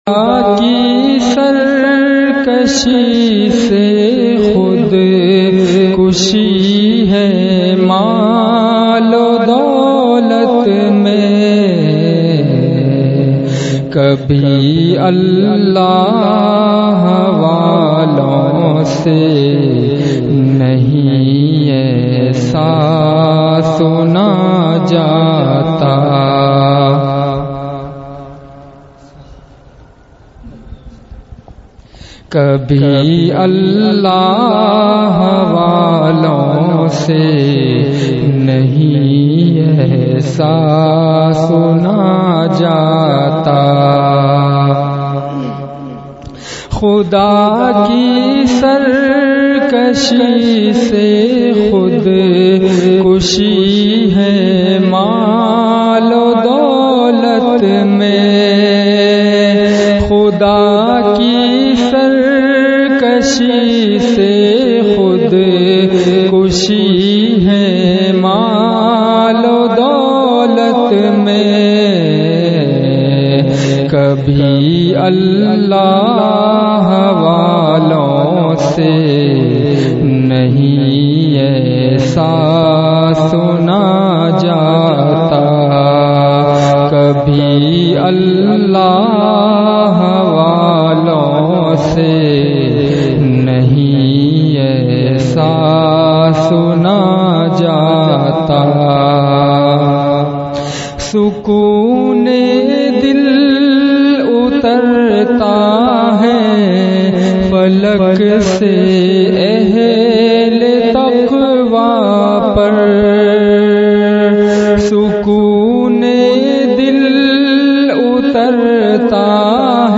حضرت کا مدرسہ اصحاب کہف گودھرا میں بیان